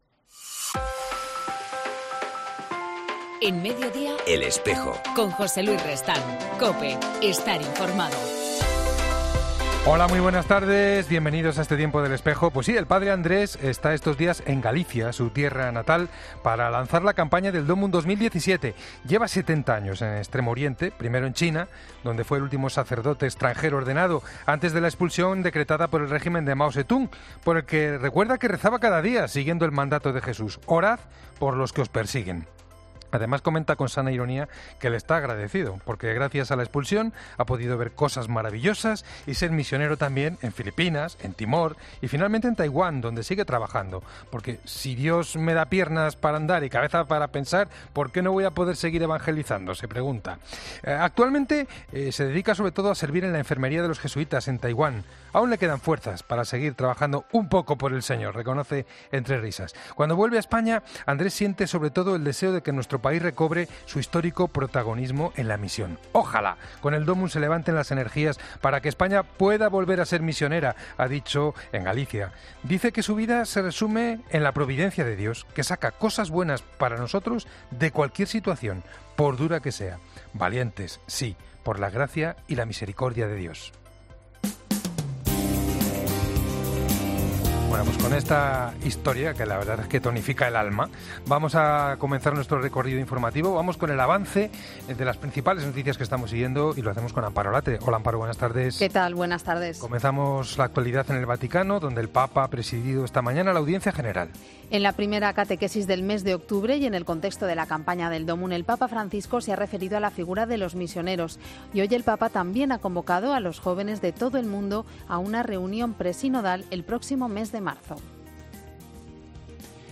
En El Espejo del 4 de octubre hablamos con Julio Parrilla, obispo de Riobamba